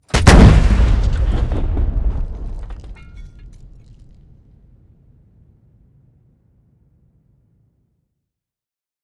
artillery-shoots-2.ogg